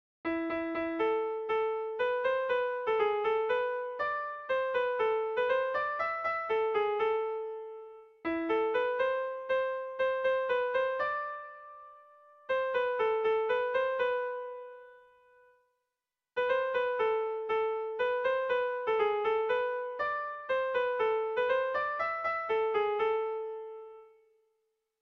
Tragikoa
ABDE